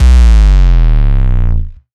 Warm 808.wav